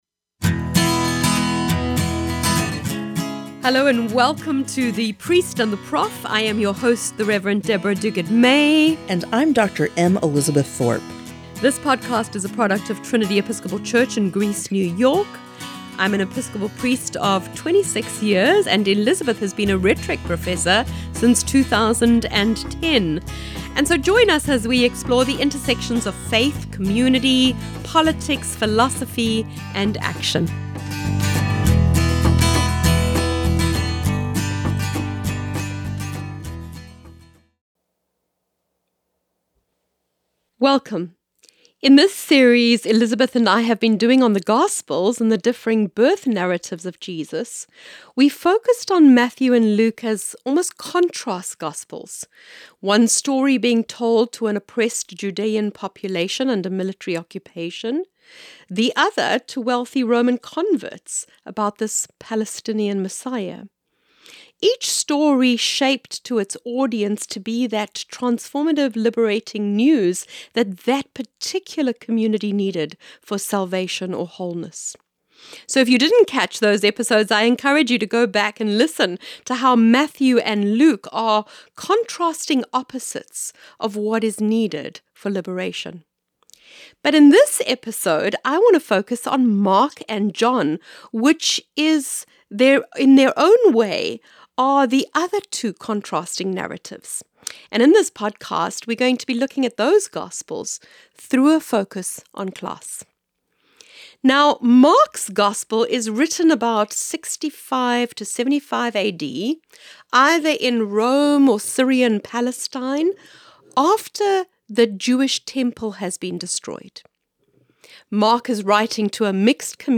solo-cast